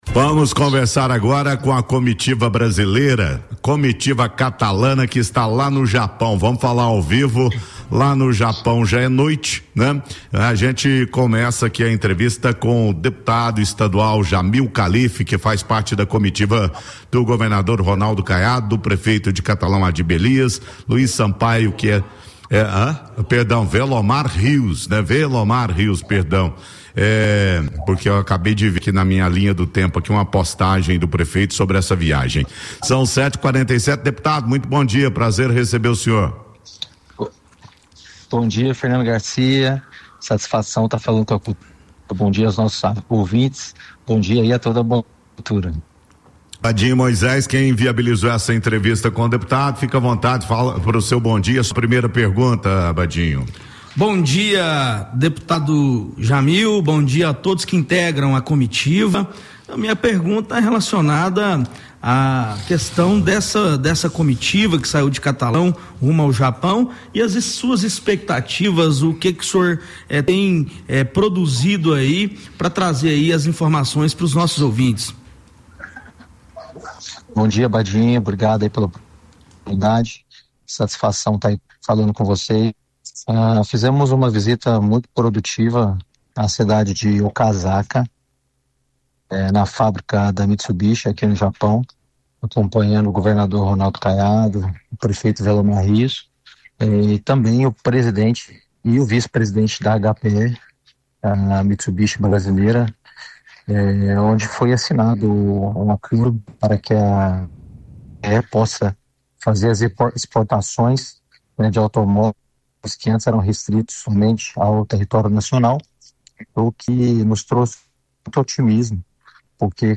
Durante entrevista ao vivo à Rádio Cultura de Catalão, direto do Japão, o deputado estadual Jamil Calife comentou os principais resultados da missão internacional no Japão liderada pelo governador Ronaldo Caiado.
Ouça a entrevista do Deputado Jamil Calife na íntegra: